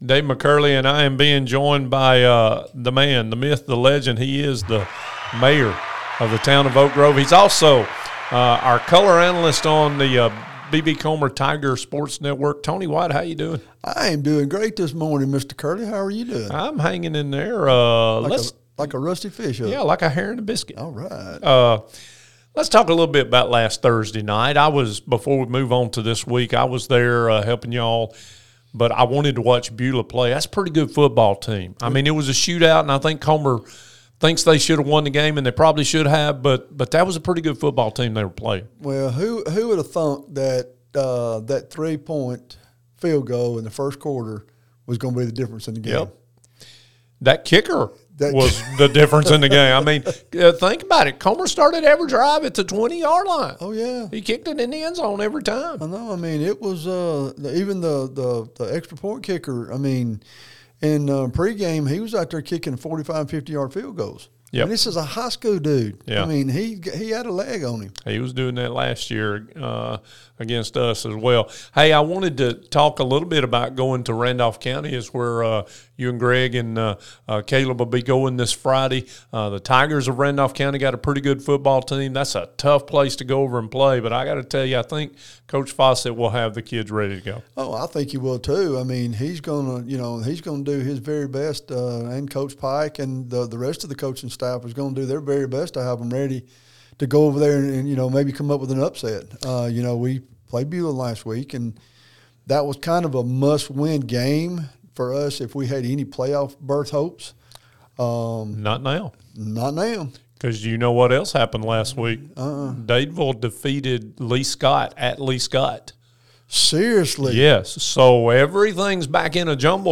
Interview with Tony White, the Mayor of Oak Grove